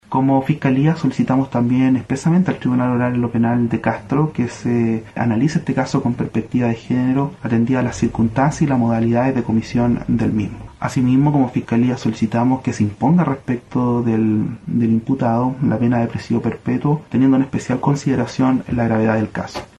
El fiscal jefe de Quellón, Fabián Fernández, señaló que las diligencias realizadas junto a la Policía de Investigaciones fueron clave, destacando las pericias del Servicio Médico Legal de Castro y Puerto Montt para confirmar la violencia sufrida por la víctima y el carácter femicida del ataque.